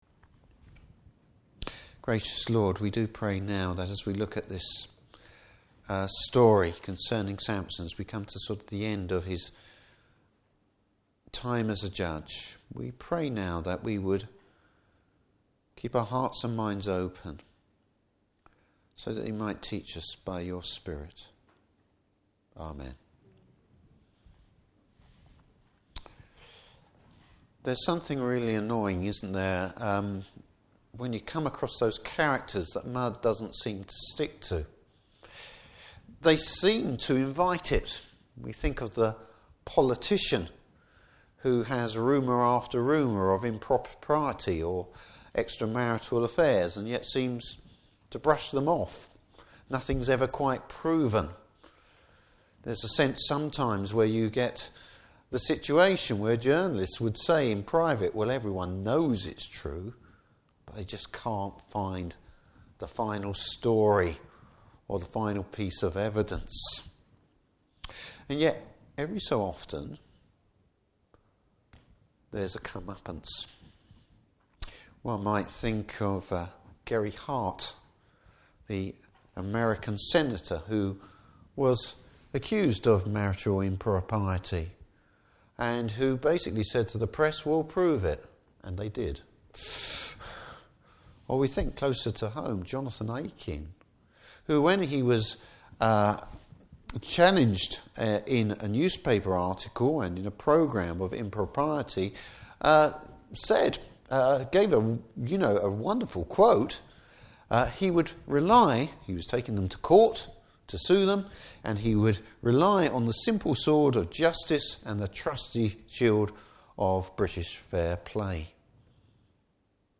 Service Type: Evening Service Bible Text: Judges 16:1-22.